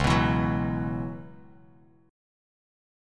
Listen to C#m11 strummed